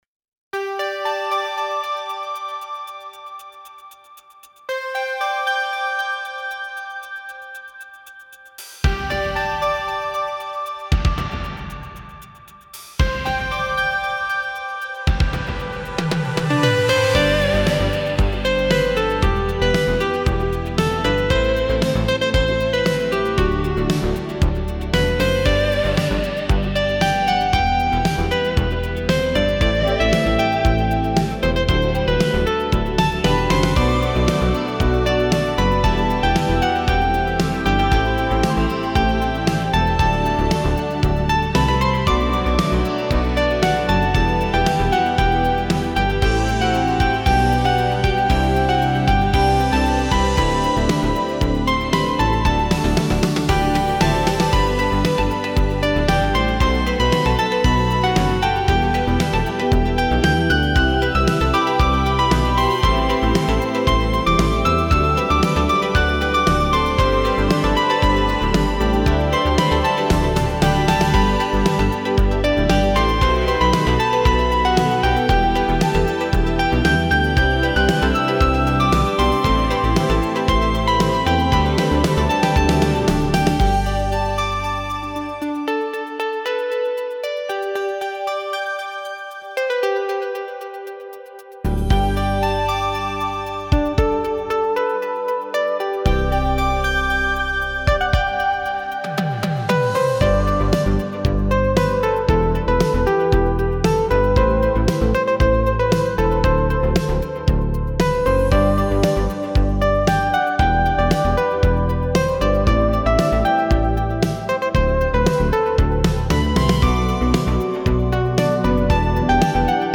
夏らしい曲が完成！